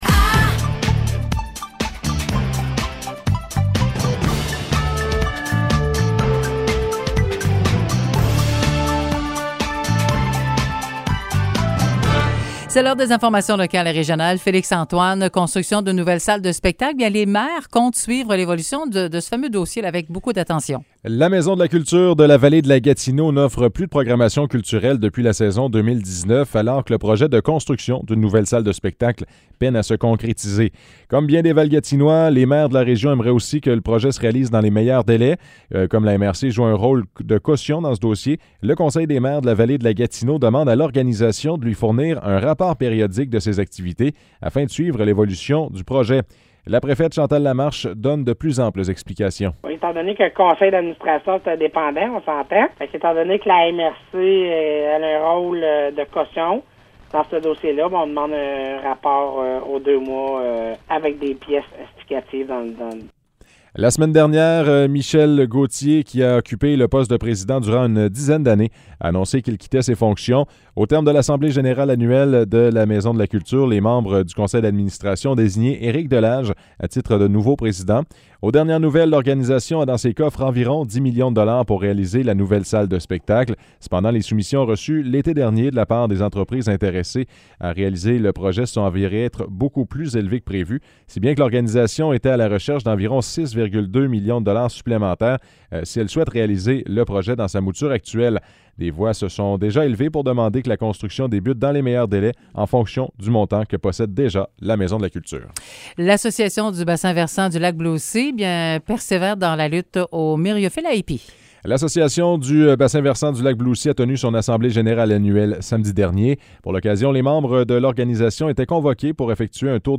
Nouvelles locales - 26 octobre 2021 - 10 h